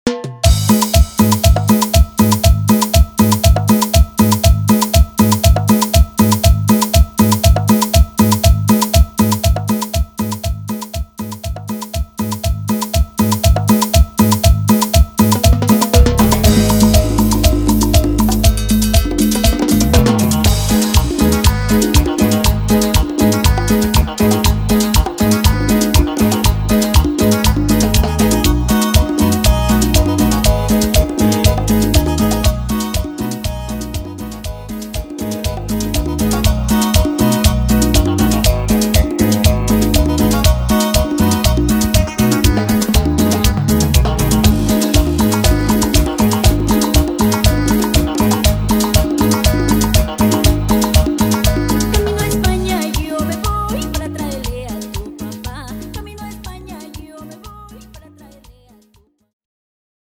Etiqueta: Cumbia